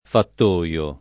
fattoio [ fatt 1L o ] s. m.; pl. ‑toi